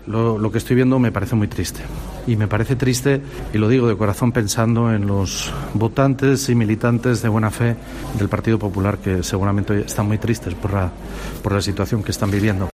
En declaraciones a los periodistas tras presidir la reunión del Consejo de Gobierno en Santo Adriano, el jefe del Ejecutivo asturiano ha considerado que "los votantes y militantes de buena fe del PP seguramente están muy tristes por la situación que están viviendo".